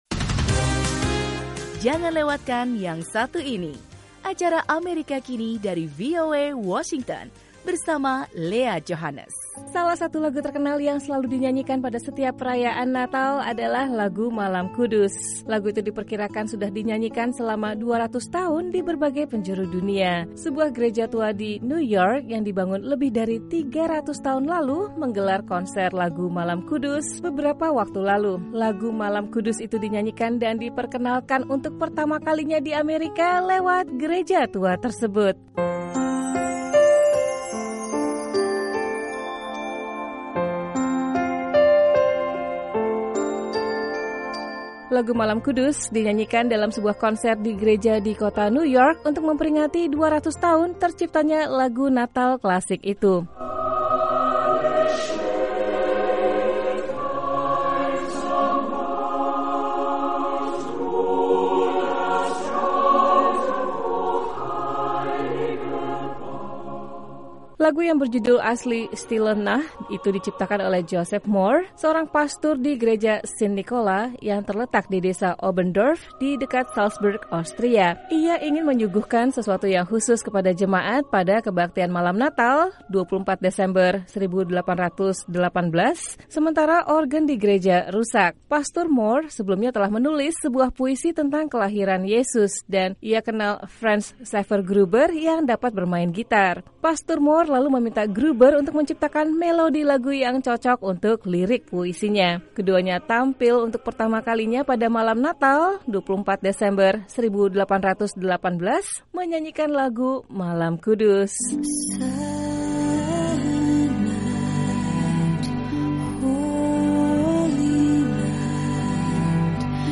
Lagu "Malam Kudus" sudah dinyanyikan selama 200 tahun di berbagai penjuru dunia. Sebuah gereja tua di New York yang dibangun lebih dari 300 tahun lalu menggelar konser lagu “Malam Kudus” beberapa waktu lalu.